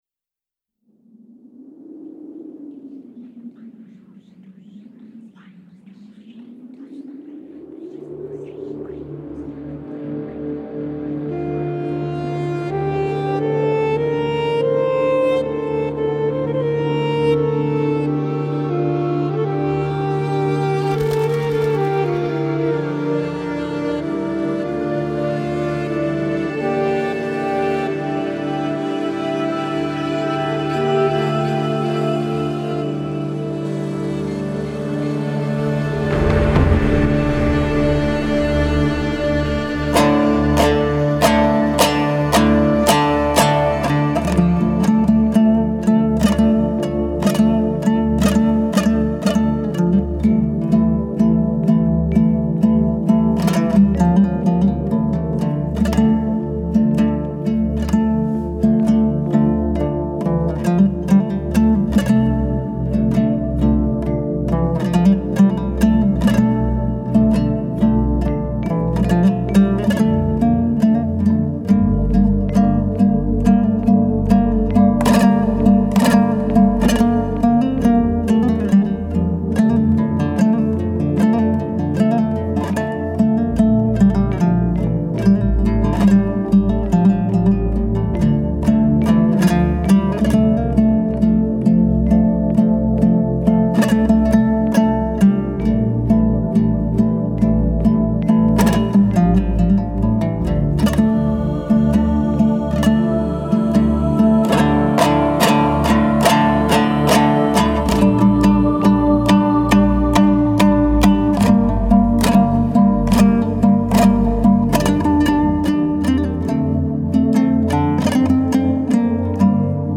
это произведение в жанре күй